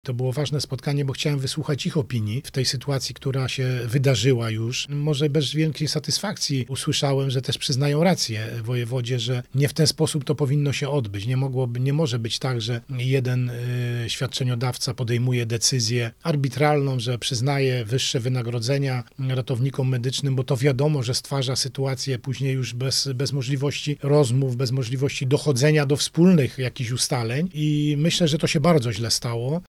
– To się bardzo źle stało – mówi wojewoda lubuski Władysław Dajczak.
a.-wojewoda-o-ratownikach-medycznych-1.mp3